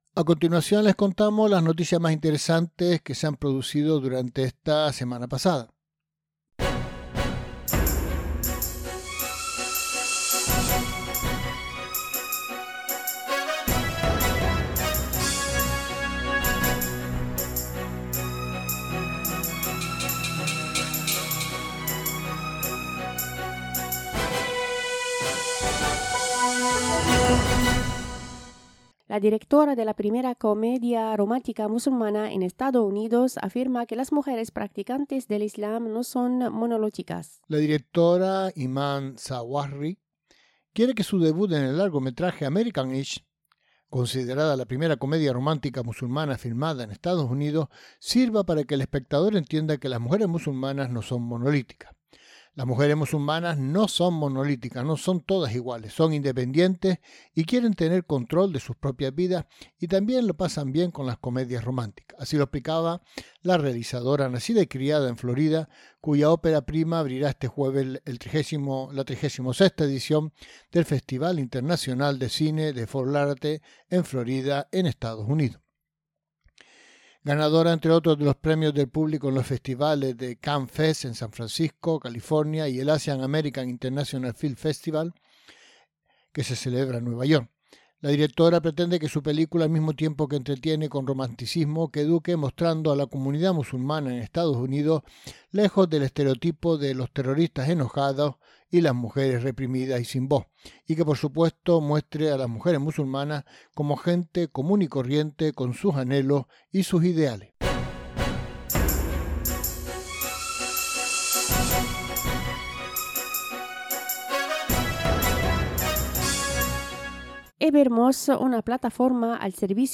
Resumen de las noticias más destacadas de la semana recogida por Radio 2 Luces.